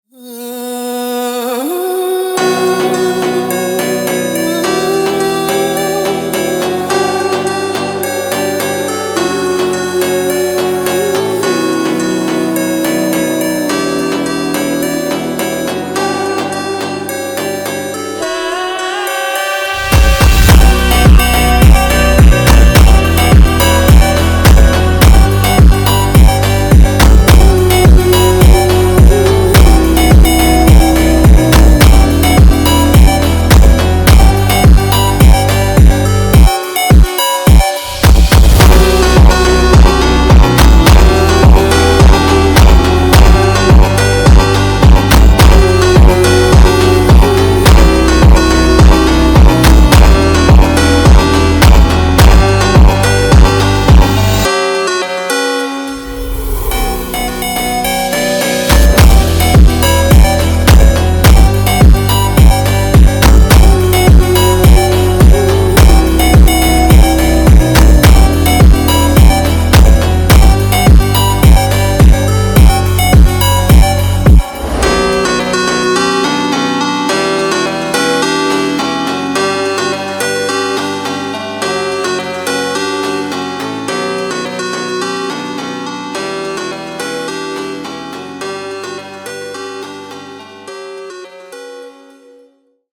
Brazilian Funk + Phonk